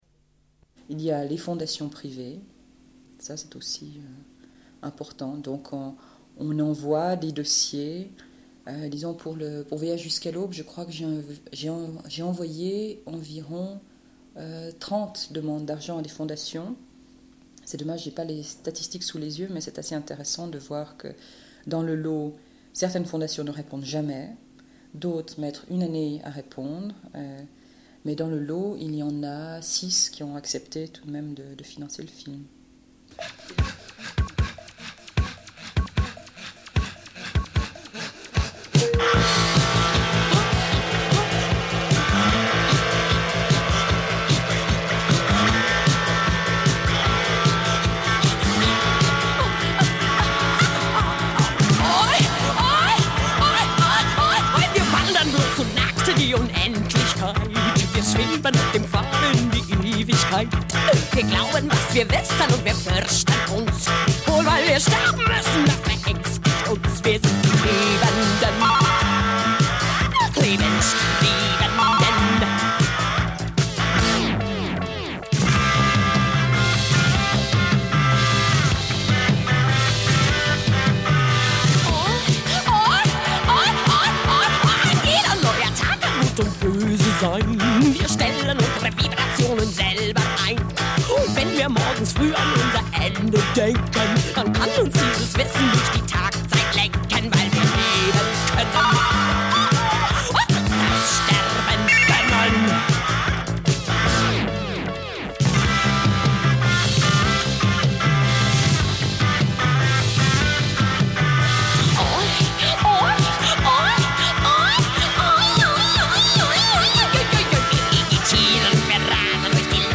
Une cassette audio, face B46:01